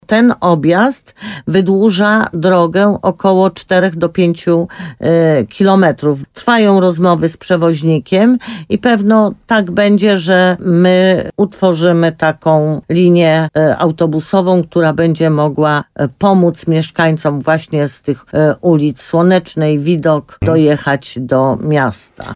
Jak powiedziała w programie Słowo za Słowo w radiu RDN Nowy Sącz burmistrz Limanowej Jolanta Juszkiewicz, rozmowy są już na finiszu.